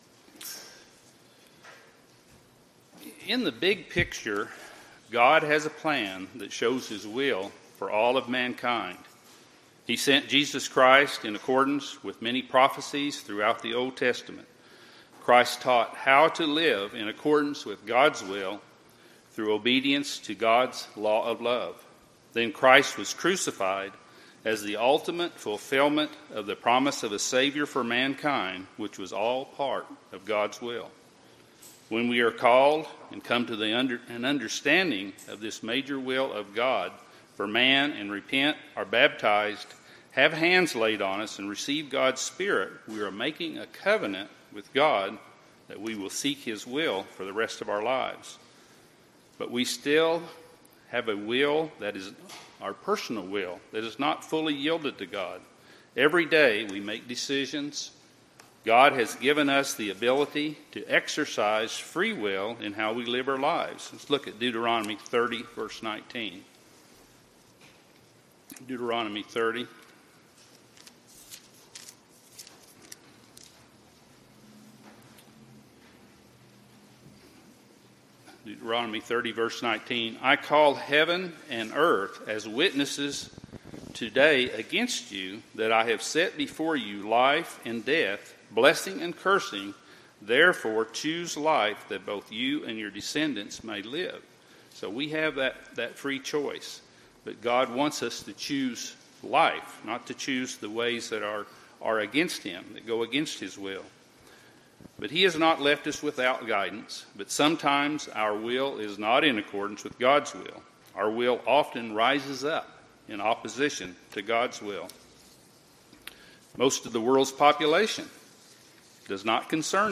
UCG Sermon Notes In the 'Big Picture', God has a plan that shows his will for all of mankind.